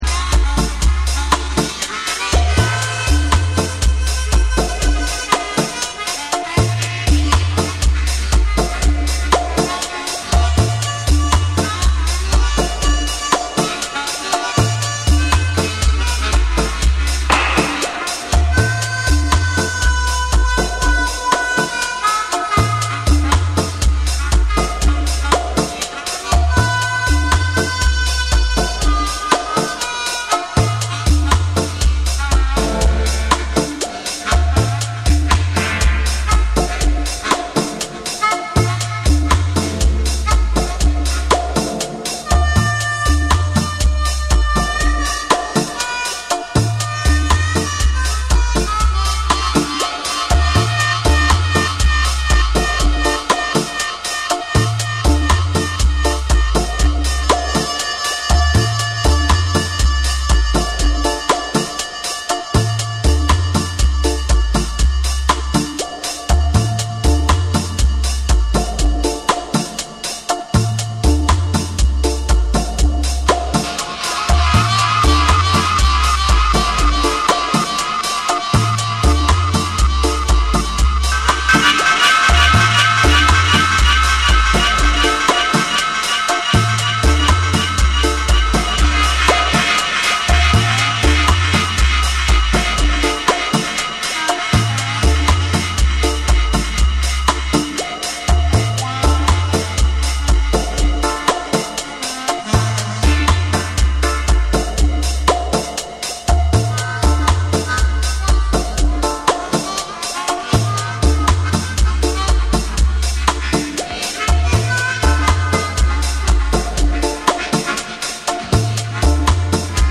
JAPANESE / TECHNO & HOUSE / REGGAE & DUB